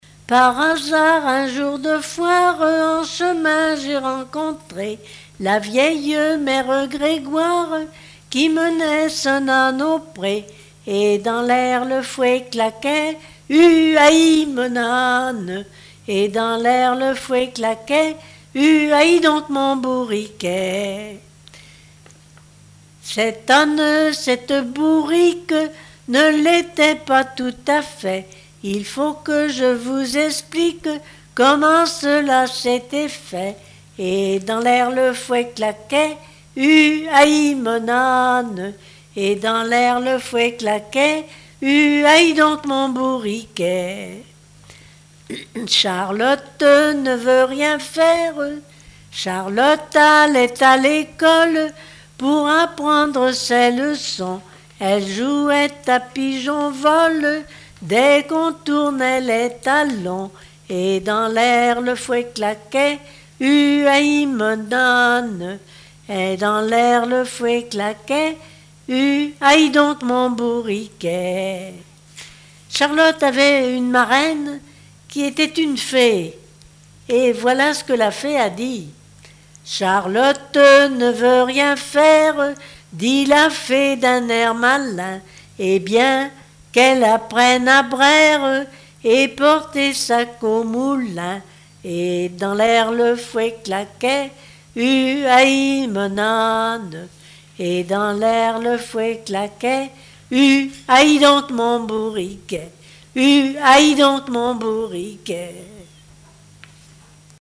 Genre strophique
Répertoire de chansons de variété
Catégorie Pièce musicale inédite